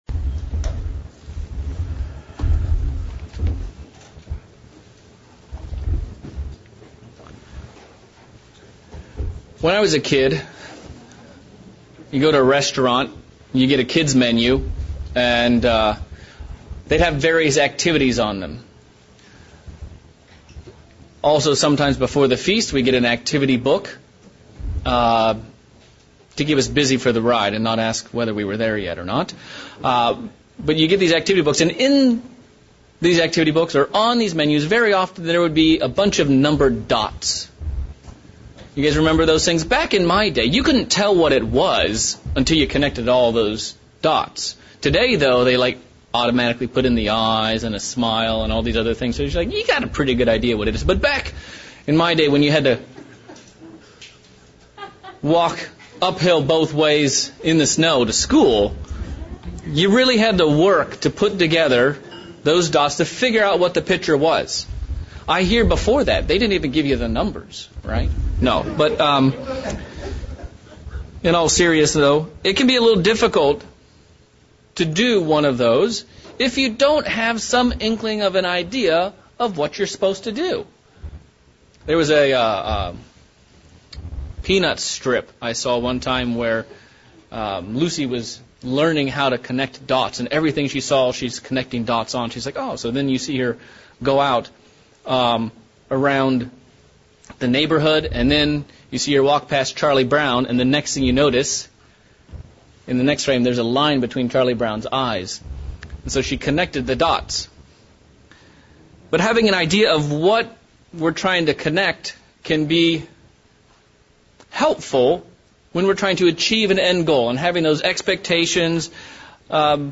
This sermon brings together a number of passages throughout the Bible to more fully understand why Jesus desperately wanted to keep the Passover with His closest followers